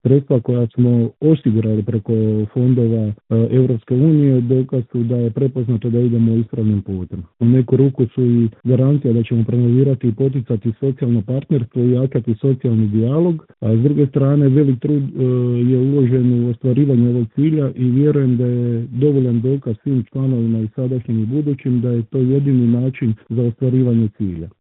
u razgovoru za Media servis